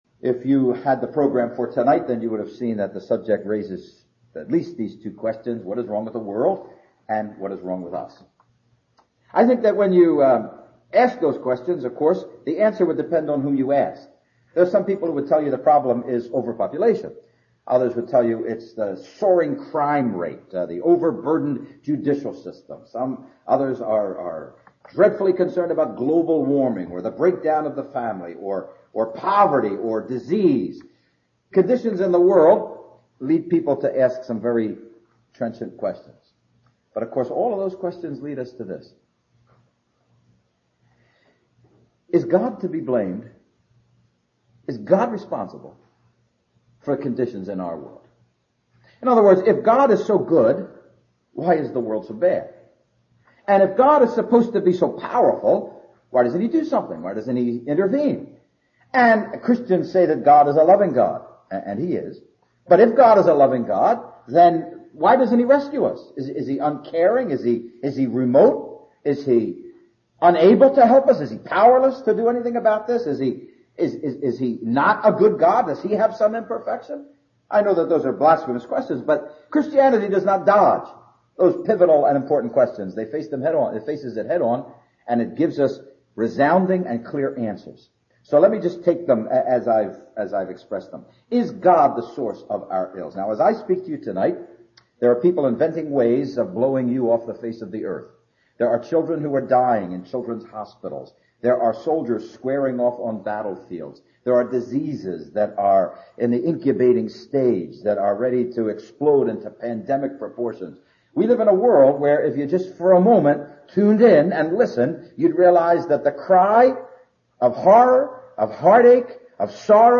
He explains how the work of Christ is the answer to the problem. (Message preached 31st July 2006)